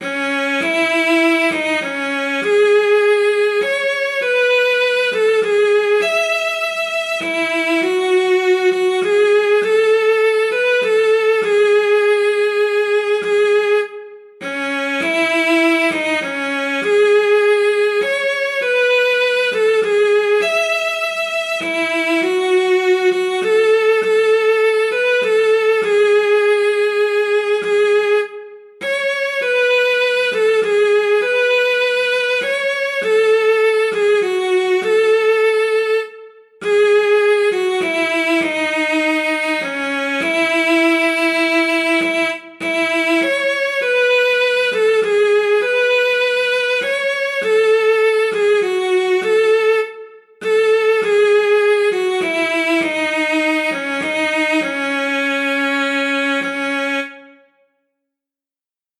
3_handprint_ballad_m2.47b_st1_fiddle_no_leap.mp3 (2.25 MB)
Audio fiddle of transcribed recording of stanza 1, sung with no leap in notes "on 'mar-riage', for ballad “Oh faine would I wive,” to “Drive the Cold Winter Away”